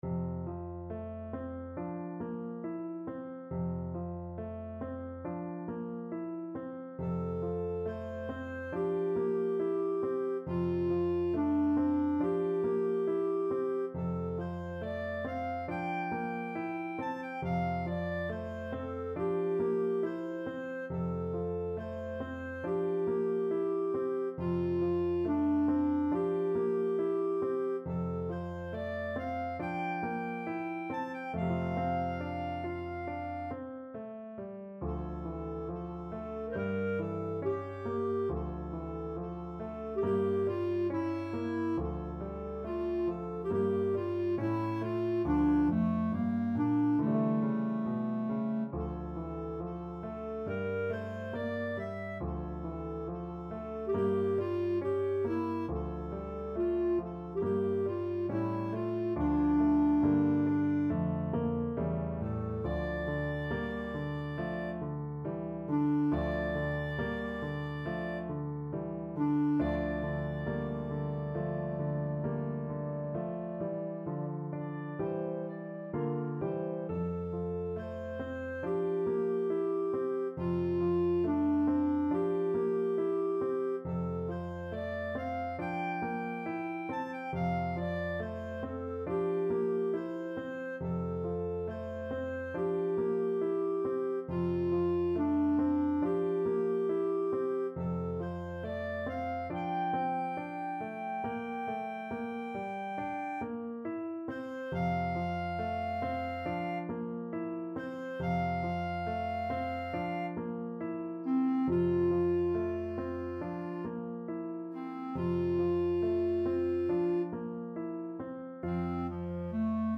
Classical Ilyinsky, Alexander Berceuse from Noure et Anitra Op.13, No.7 Clarinet version
F major (Sounding Pitch) G major (Clarinet in Bb) (View more F major Music for Clarinet )
4/4 (View more 4/4 Music)
~ =69 Poco andante
Classical (View more Classical Clarinet Music)